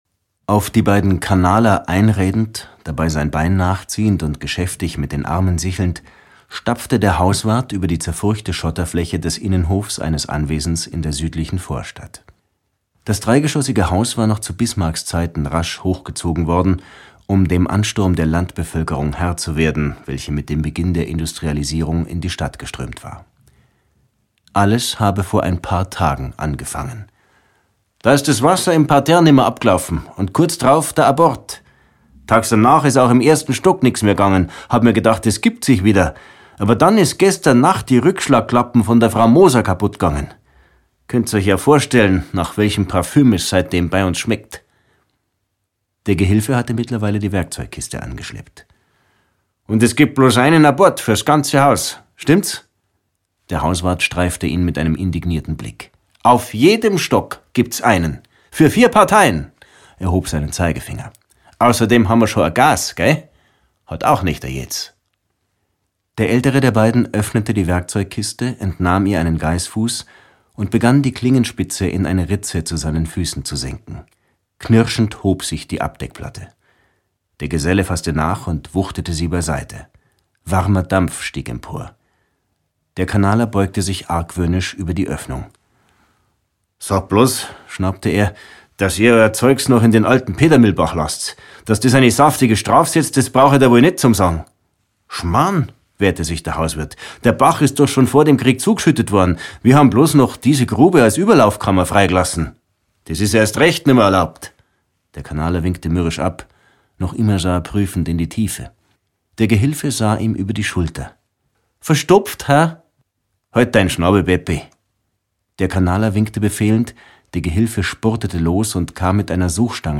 Krimi to go: Ende der Ermittlungen - Robert Hültner - Hörbuch